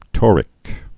(tôrĭk)